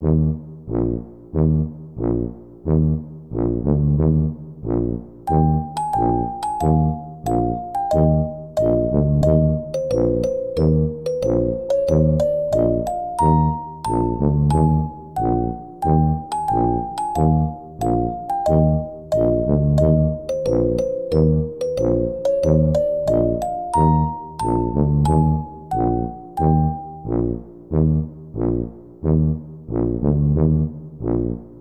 Звуки тубы
Звук движущегося гиппопотама в категории Туба музыкальный инструмент